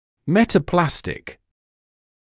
[ˌmetə’plæstɪk][ˌмэтэ’плэстик]метапластический, относящийся к метаплазии